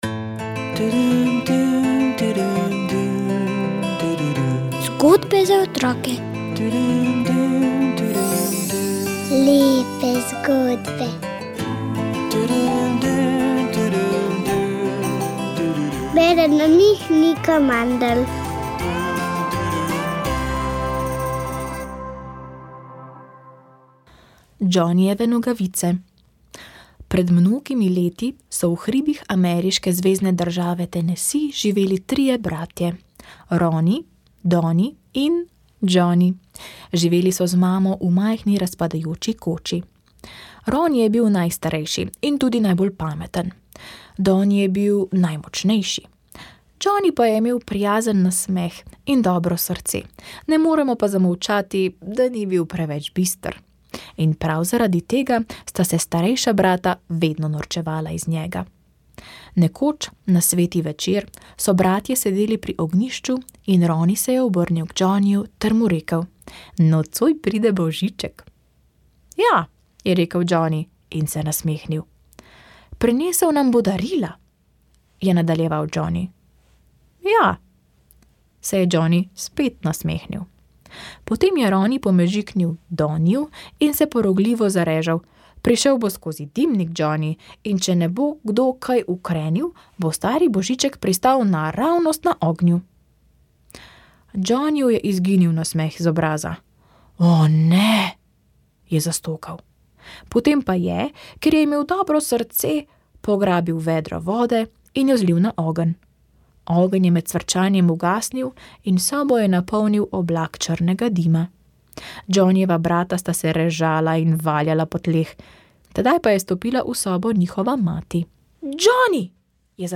Gre za izjemno oddaljeno galaksijo, v kateri so astronomi našli najbolj oddaljene potrjene zvezdne kopice doslej. Prisluhnite zanimivemu pogovoru o odmevnosti odkritja, o tem, kaj je gravitacijsko lečenje in kam je usmerjano nadaljnje raziskovanje s teleskopom James Webb.